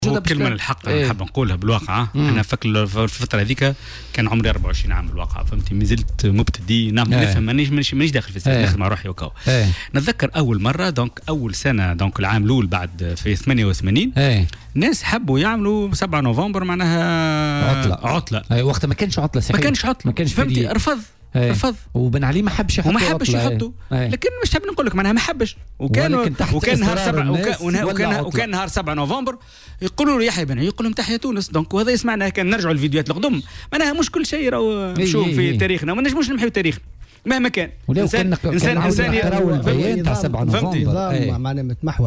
وتابع ضيف "بوليتيكا" على "الجوهرة اف أم" أن بن علي رفض وقتها بقوّة هذا المقترح في البداية قبل اقراره في نهاية المطاف بشكل رسمي.